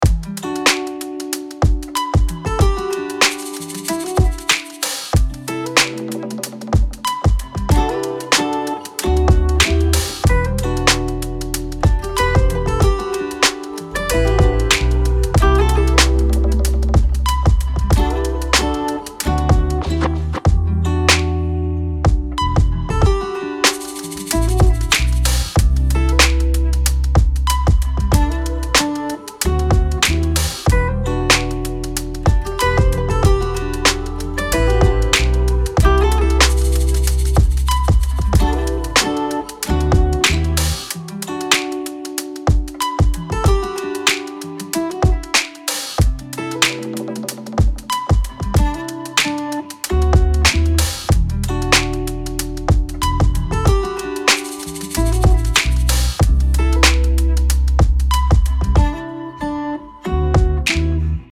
Explosive Drum Sounds
The samples in this pack are heavy-hitting, crisp, and dynamic.
The drums in this pack hit harder, are more creative, and bring a wider range sounds to the table than any other pack we’ve created to date.
•75 Powerful Drum Breaks – BPM labeled
•50 Percussion One-Shots (Cymbals, Cabasas, Chimes, etc.)
•40 Dynamic Hat & Percussion Top Loops
•35 Essential Snares & Rim Shots
•30 Booming Kick Samples
Demo